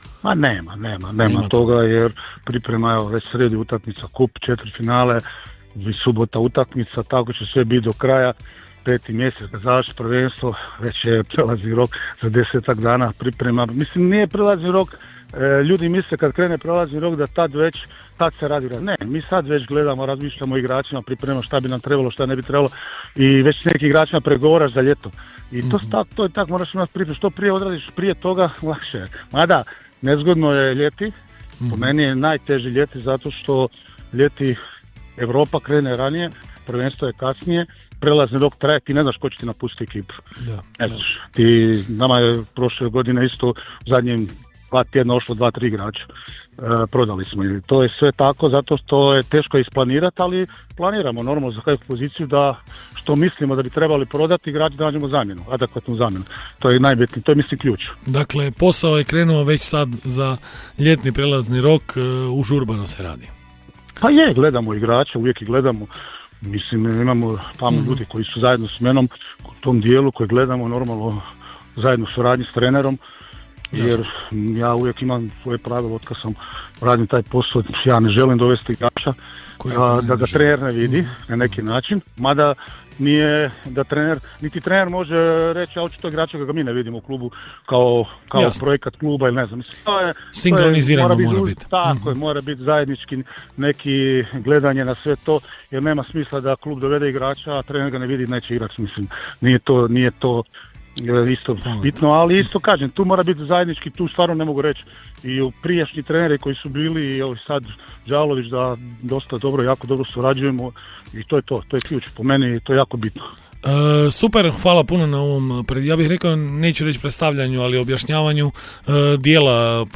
Ali, gostovanje na radiju bilo je usmjereno prema poslu sportskog direktora prvoligaša.